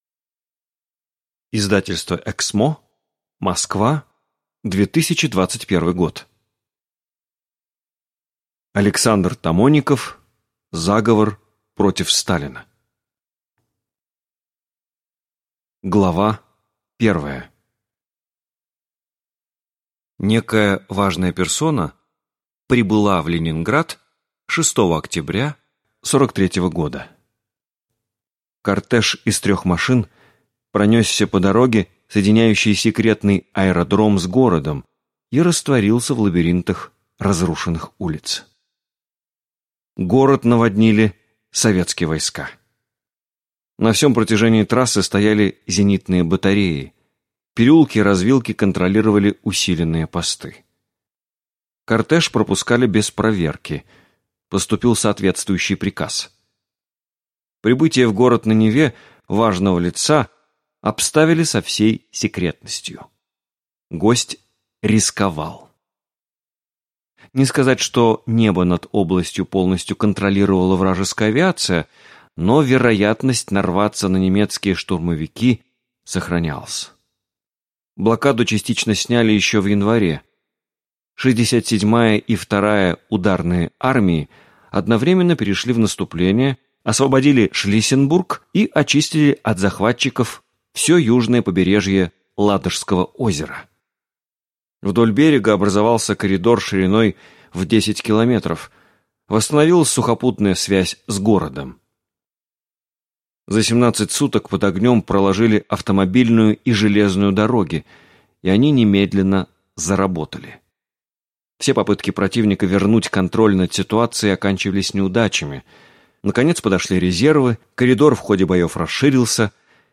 Аудиокнига Заговор против Сталина | Библиотека аудиокниг
Прослушать и бесплатно скачать фрагмент аудиокниги